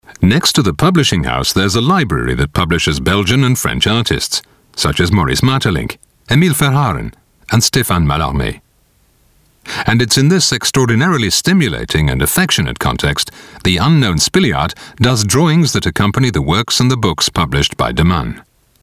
Sprecher englisch britisch.
Sprechprobe: Sonstiges (Muttersprache):
native english / british voice over talent. I am the warm voice of authority specializing in corporate videos, upmarket advertising, e-learning, documentary